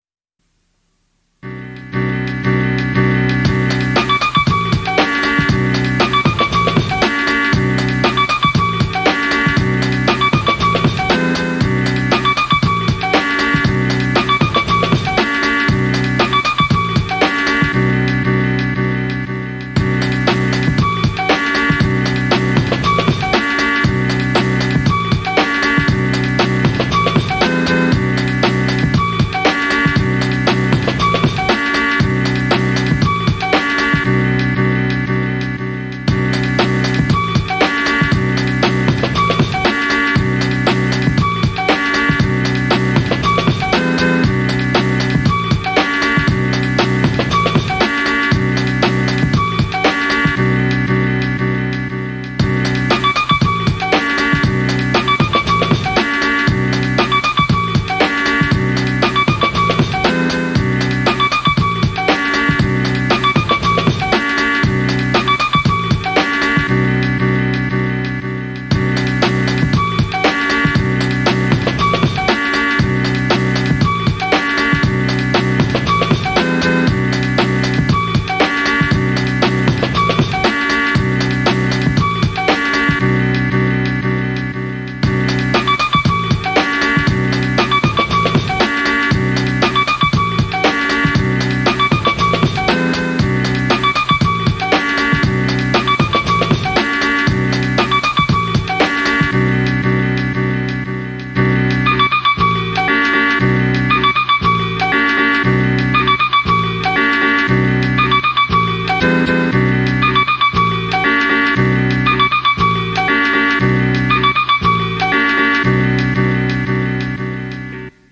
3rd old beat
hip hop instrumental remix chill lofi chillhop 90s drum and bass dance fast